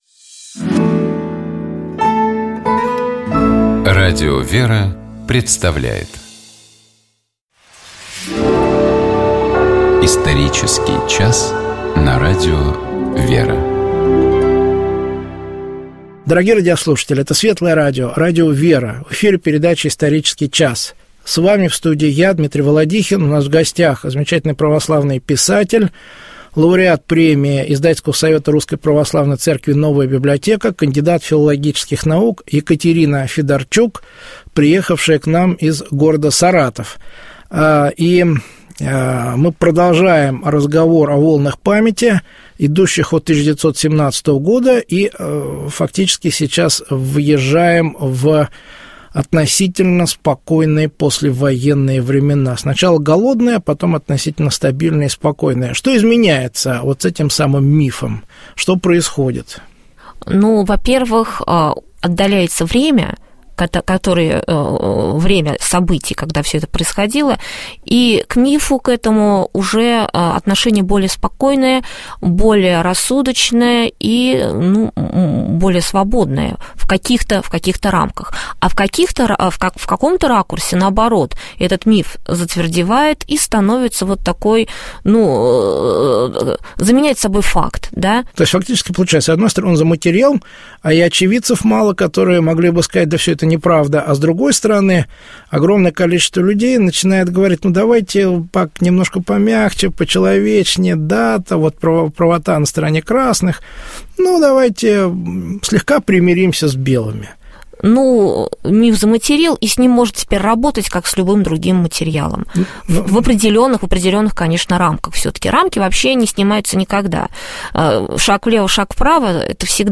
Гость программы — писатель, кандидат филологических наук